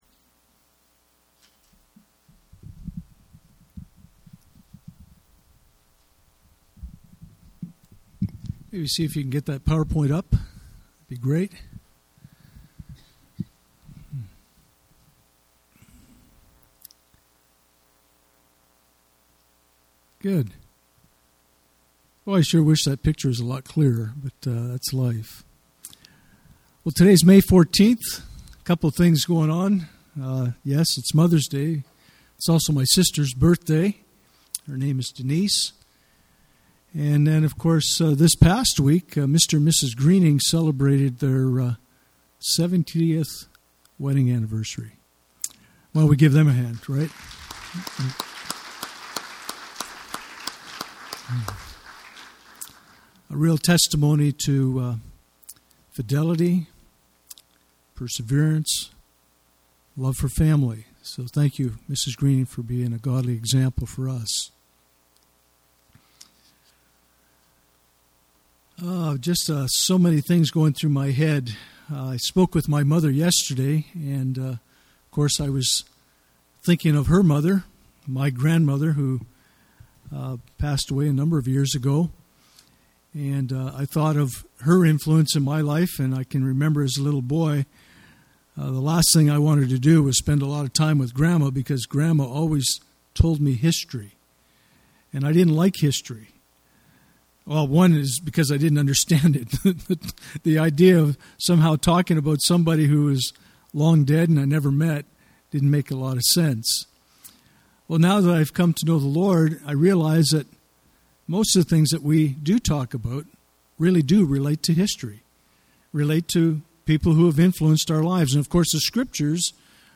Influencing Our Culture Passage: Proverbs 31:10-20 Service Type: Sunday Morning « Living in an Eroding Culture